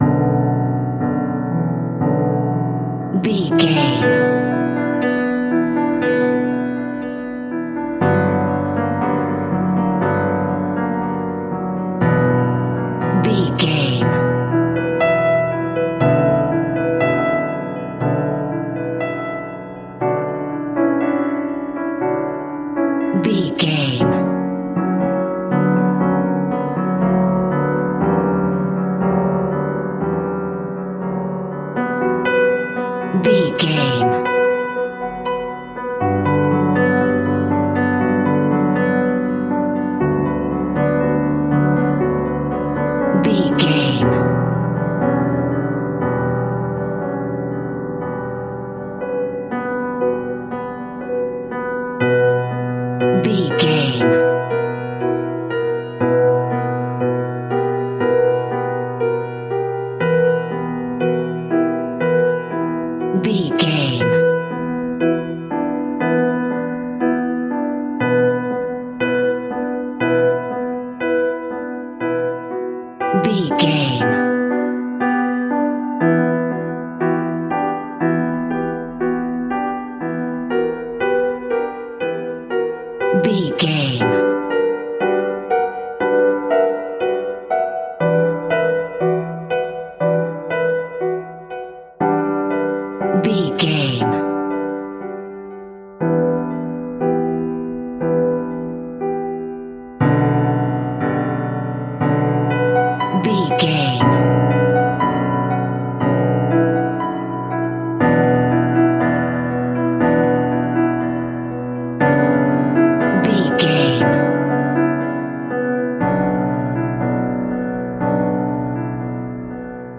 Aeolian/Minor
scary
tension
ominous
dark
suspense
haunting
eerie
creepy